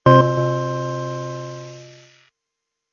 键盘SA10声音库 " 65大教堂 - 声音 - 淘声网 - 免费音效素材资源|视频游戏配乐下载